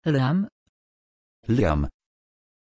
Pronunciation of Lyam
pronunciation-lyam-pl.mp3